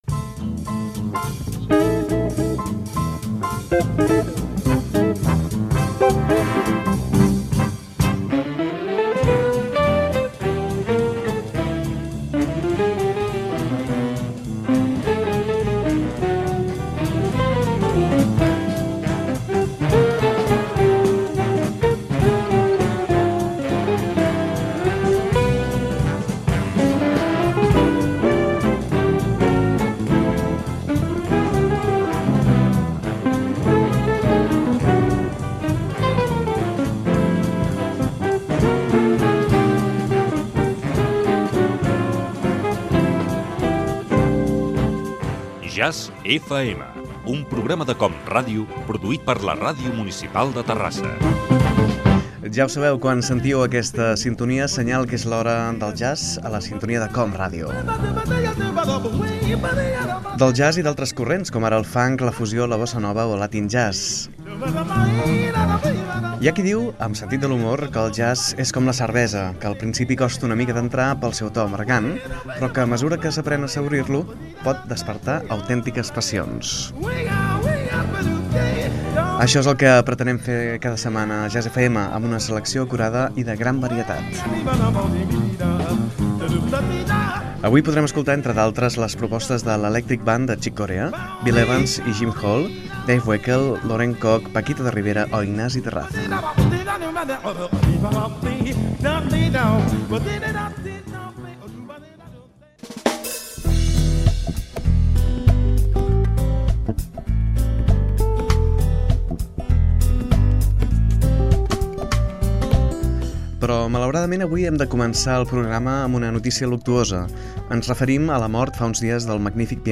Sintonia, identificació i prersentació del programa
Musical